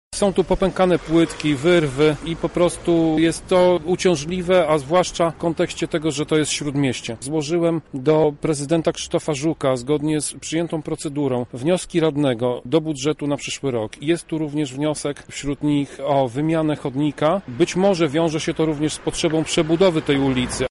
Jak wygląda chodnik na Śródmieściu, opisuje radny Prawa i Sprawiedliwości Tomasz Pitucha: